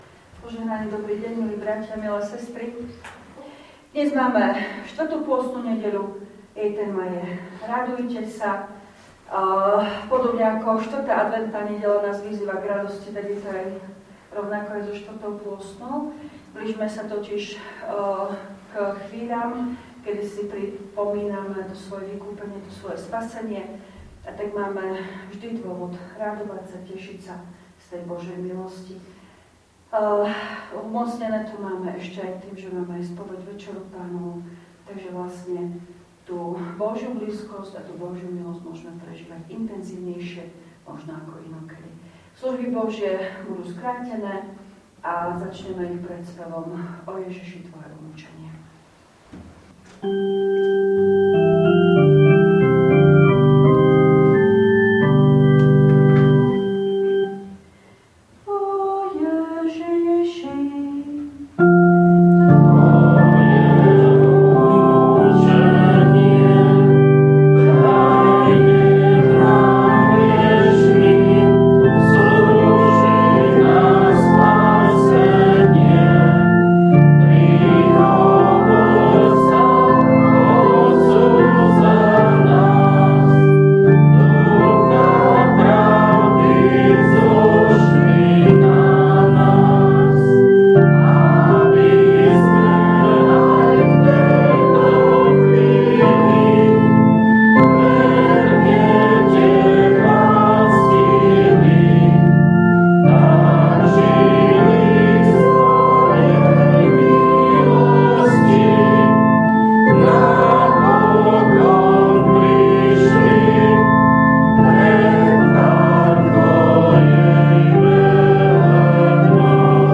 V nasledovnom článku si môžete vypočuť zvukový záznam zo služieb Božích – 4. nedeľa pôstna.